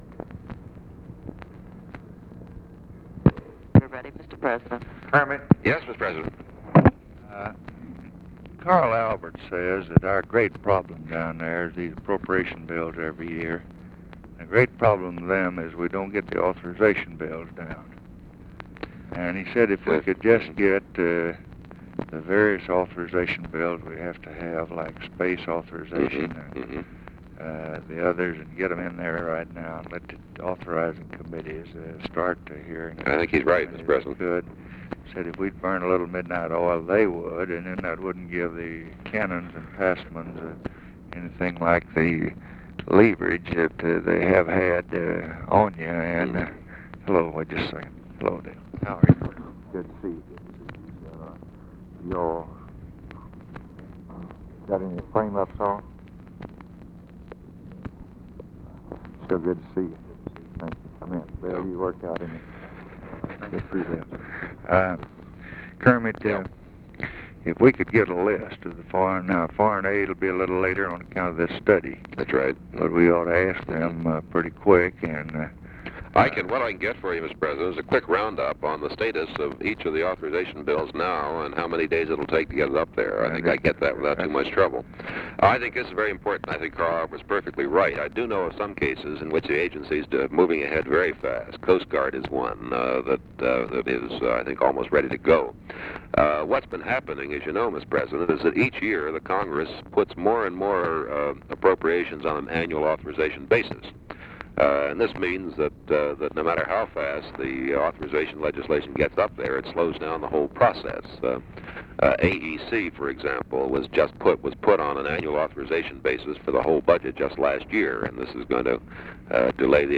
Conversation with KERMIT GORDON, January 9, 1964
Secret White House Tapes